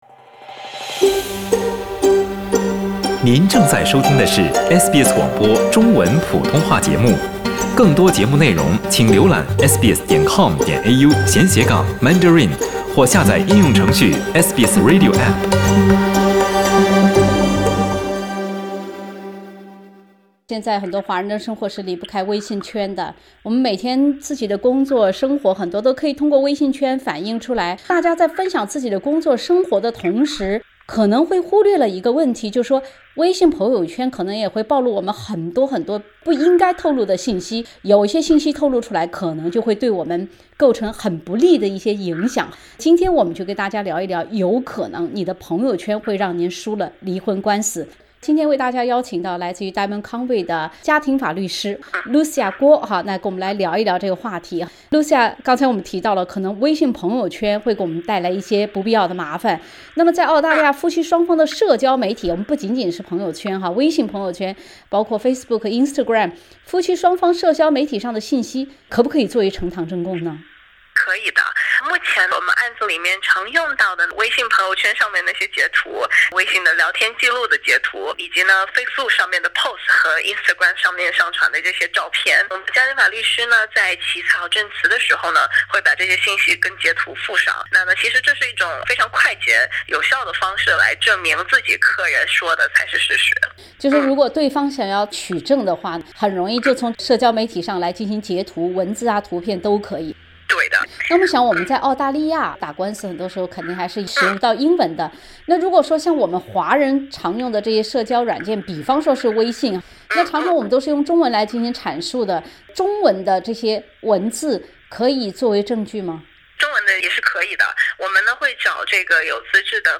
社交媒体发图发文要谨慎，可能不小心就会成为离婚官司中对自己不利的“铁证”。（点击封面图片，收听完整采访）